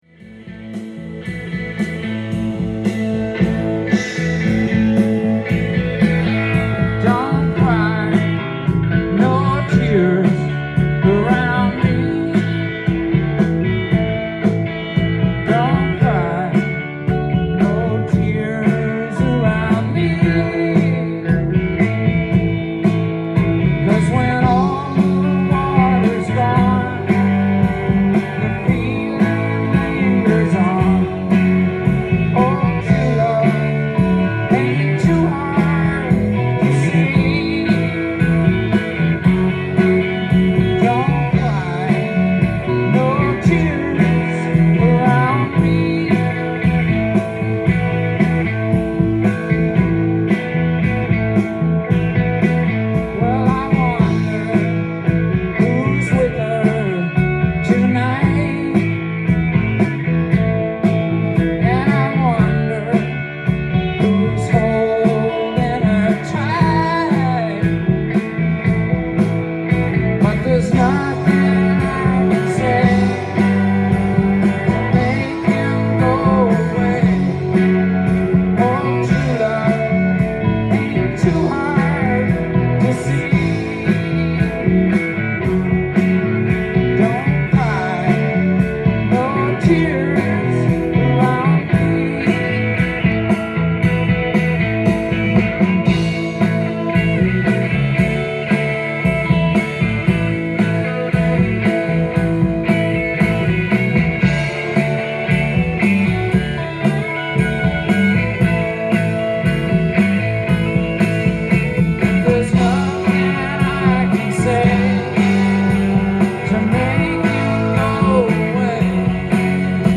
ジャンル：ROCK & POPS
店頭で録音した音源の為、多少の外部音や音質の悪さはございますが、サンプルとしてご視聴ください。